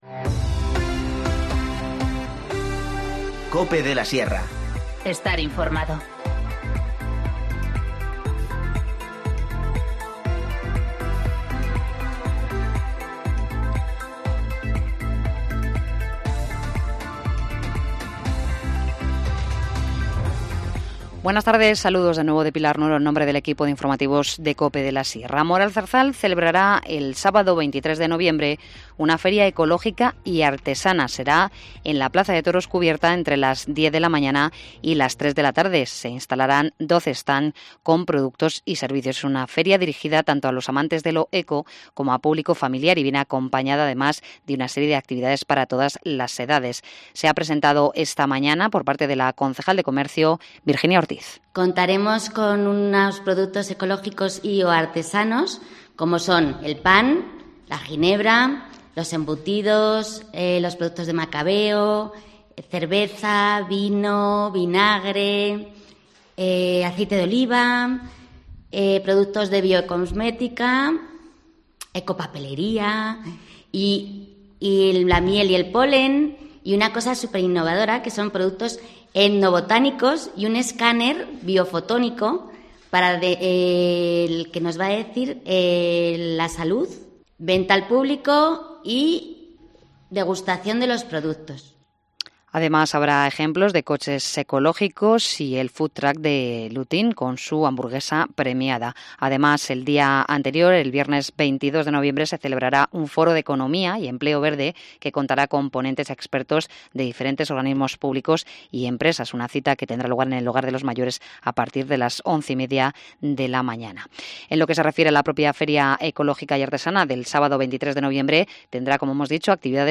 Informativo Mediodía 13 noviembre 14:50h